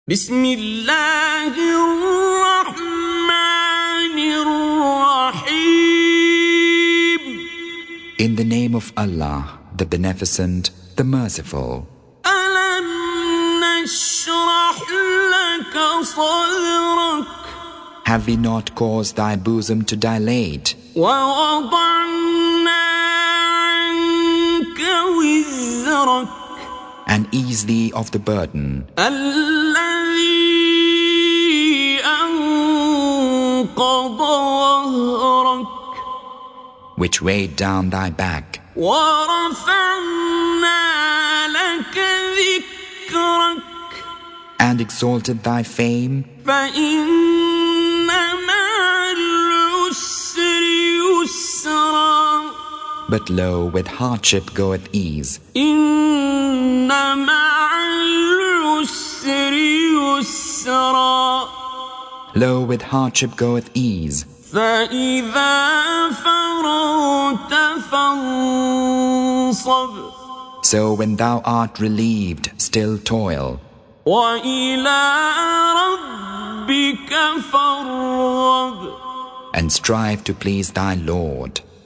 Surah Sequence تتابع السورة Download Surah حمّل السورة Reciting Mutarjamah Translation Audio for 94. Surah Ash-Sharh سورة الشرح N.B *Surah Includes Al-Basmalah Reciters Sequents تتابع التلاوات Reciters Repeats تكرار التلاوات